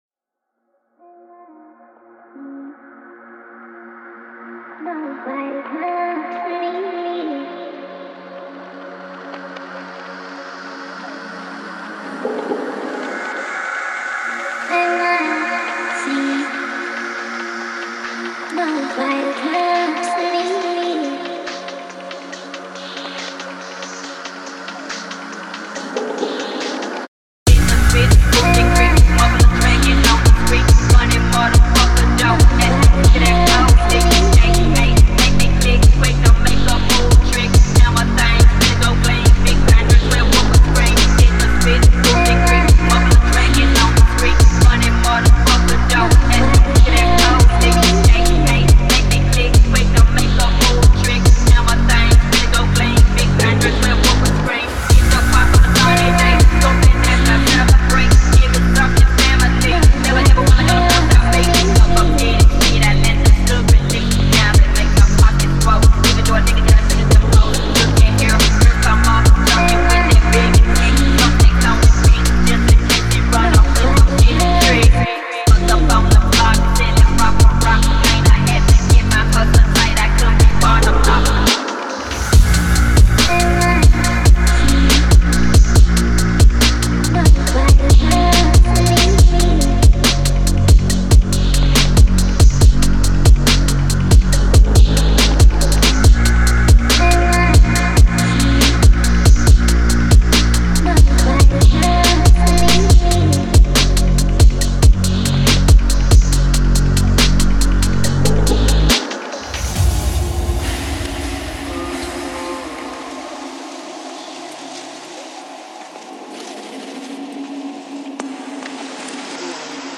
Категория: Фонк музыка
Phonk треки